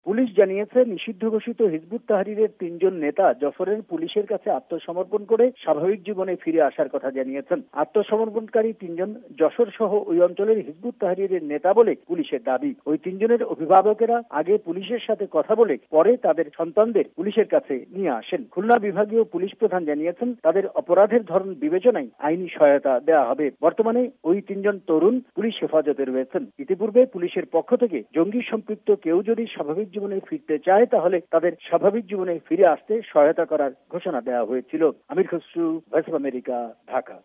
ঢাকা থেকে